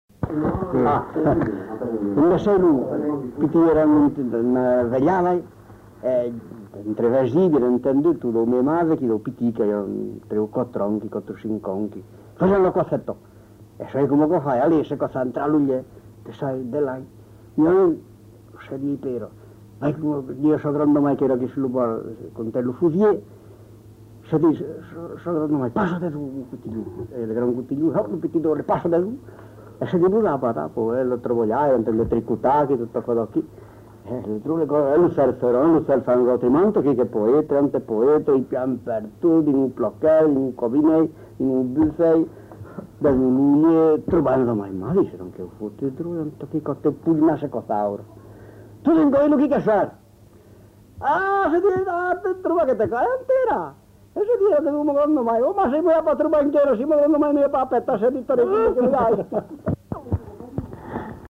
Aire culturelle : Périgord
Genre : conte-légende-récit
Type de voix : voix d'homme
Production du son : parlé
Classification : conte facétieux